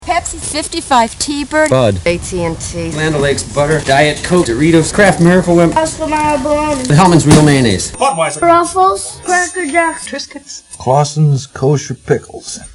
It was a play-on-tape, without any sort of clues as to what was occurring.
That was bad enough, but horrible acting made the concept even worse. Of course, that's not yet mentioning the fact that it all sounded like it was recorded in an echoing room with two mics (to give it that stereo "quality").